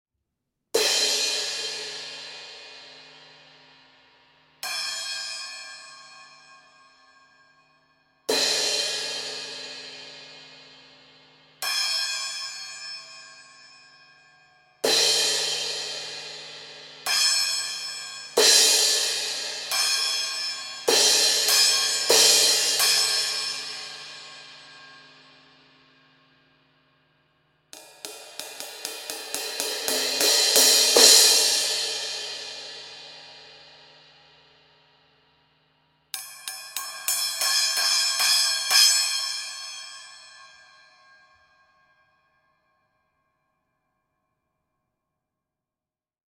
Here’s how the Praxis cymbals sound recorded side by side with our Heartbeat Classic series cymbals:
Compare Heartbeat Classic 16″ crash to Praxis 16″ crash:
16__Classic-Crash-_-Practice-Crash.mp3